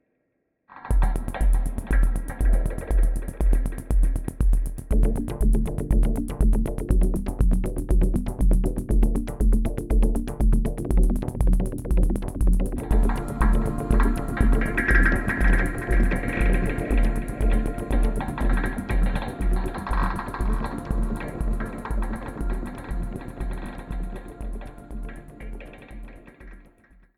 Audiorecording, Musik & Sounddesign